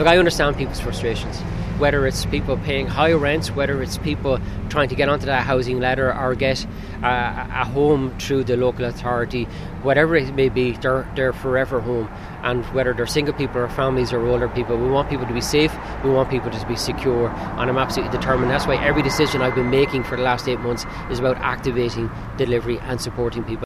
Housing Minister James Browne says he knows many people are fed up with the housing situation…………….